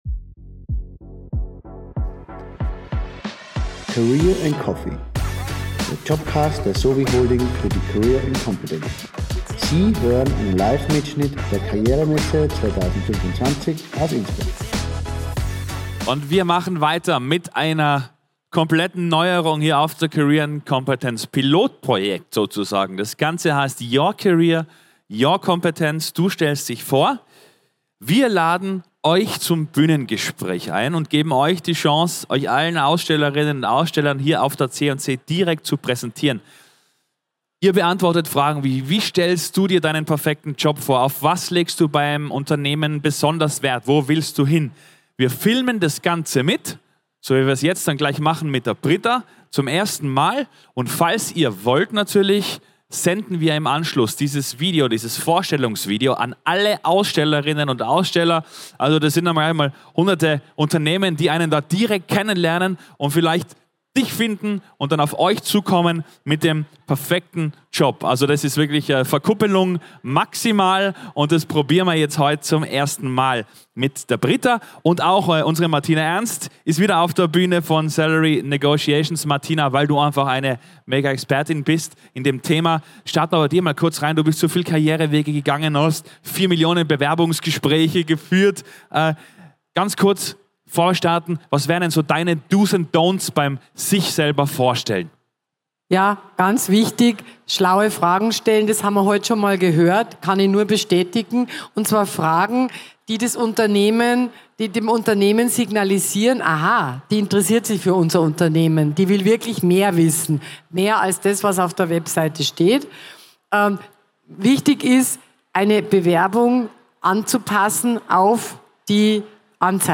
Livemitschnitt #9 von der career & competence am 14. Mai 2025 im Congress Innsbruck.